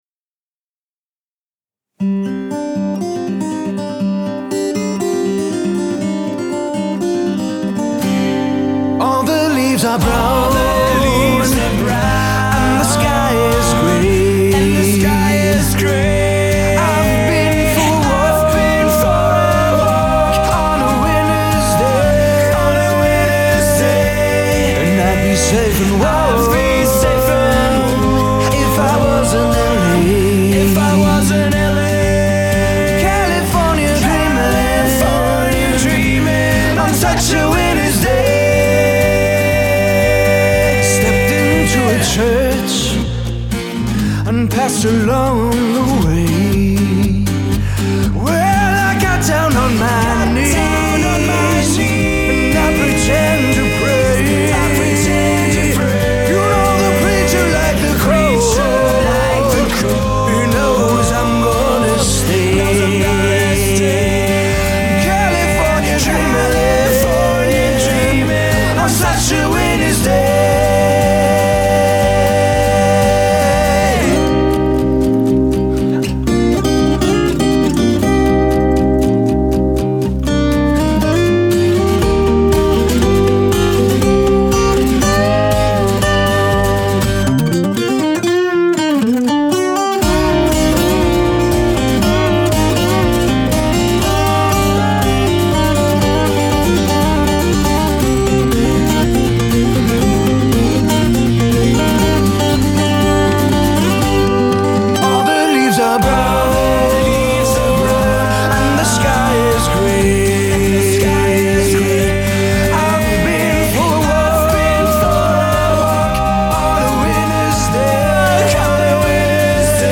handgemachten Acoustic-Rock tanzbare Lagerfeuerstimmung
• Coverband